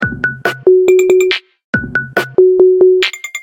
FunkyTempo2.ogg